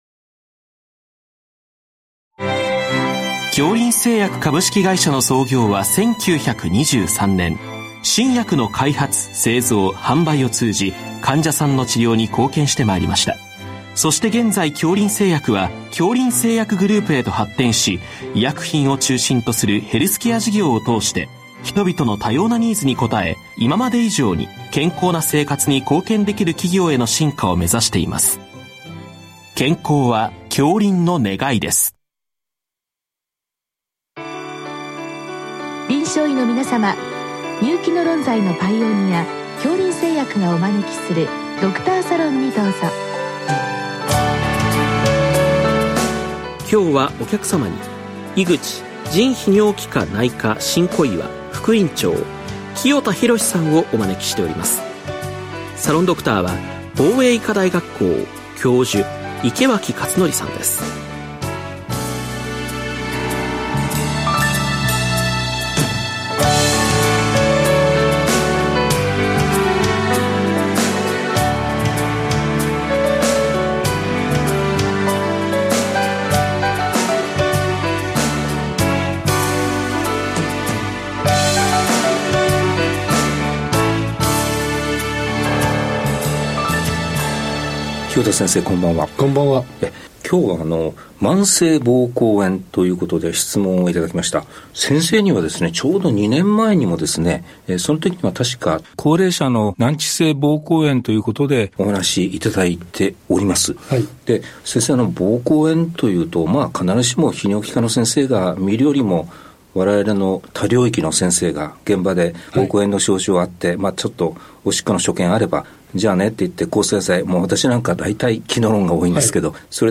全国の臨床医の方々にむけた医学情報番組。臨床医の方々からよせられたご質問に、各分野の専門医の方々にご出演いただき、解説いただく番組です。